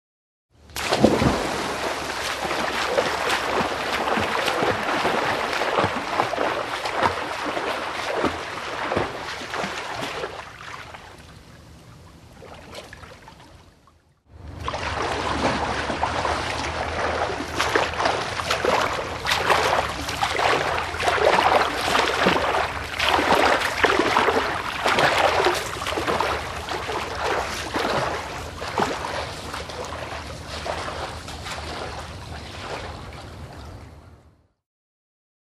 На этой странице собраны разнообразные звуки прыжков в воду: от легких всплесков до мощных ударов о поверхность.
Звук всплеска при падении в воду